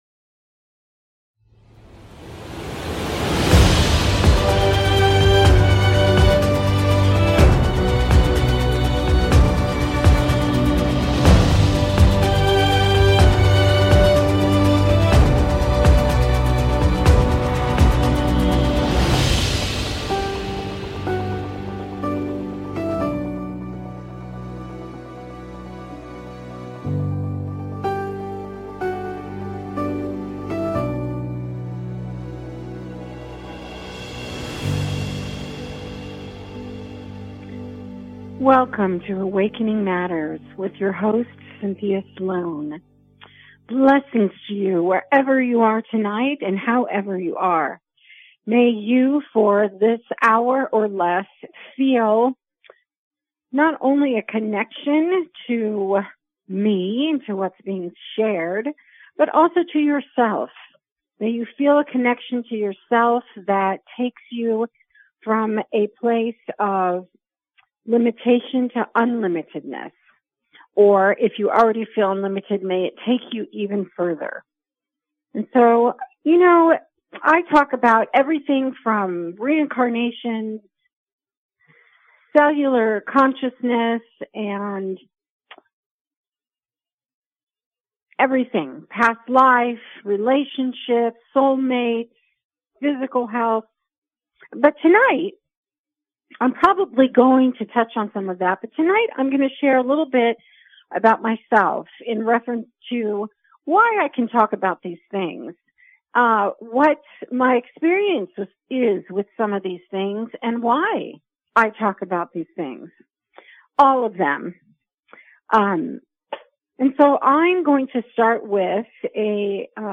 Talk Show Episode
A spiritual dialogue that invites divine wisdom, joy and laughter.